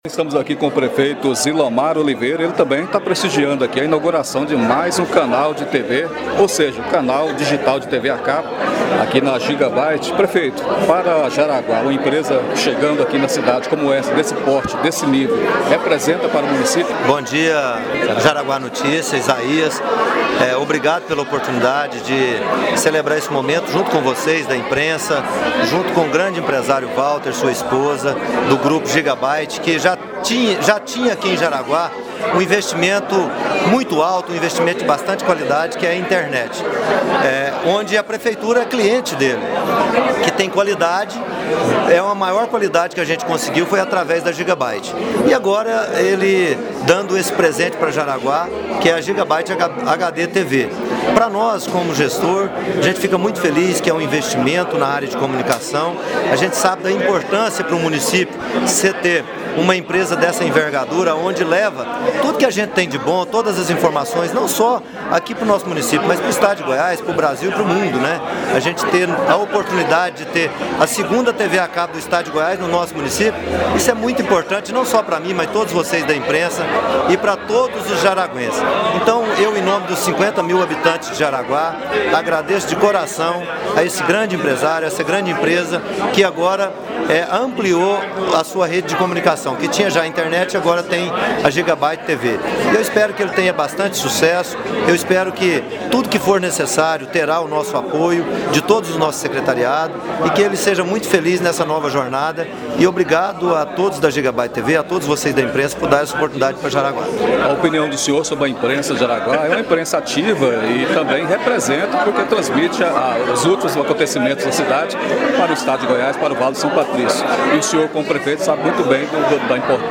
Prefeito fala sobre a imprensa de Jaraguá em inauguração de TV
Ao falar sobre a importância da chegada de mais uma modalidade de comunicação em Jaraguá, na ocasião em que inaugurava a HDTV Giga Byte Telecom, o prefeito Zilomar Oliveira (PSDB) falou sobre a impressa de Jaraguá na geração de renda e emprego para o município, bem como, citou alguns conceitos sobre a comunicação local.